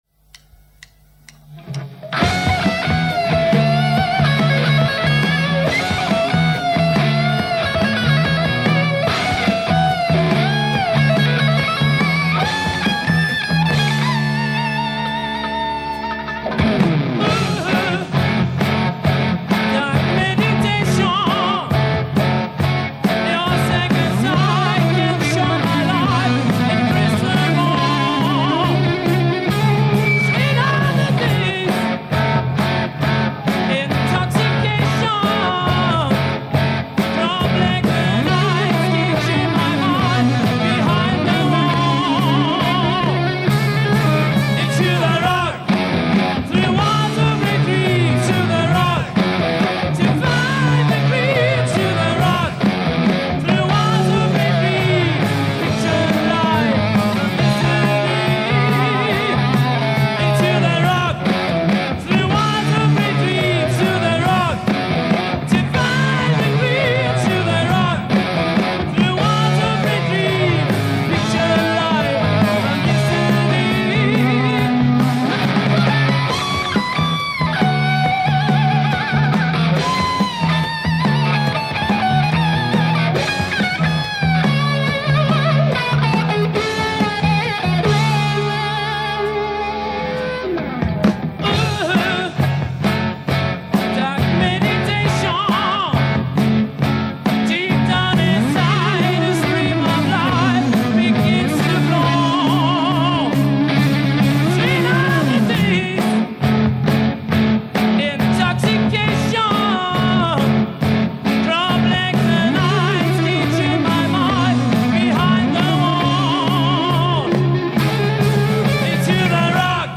Hard Rock, Heavy Metal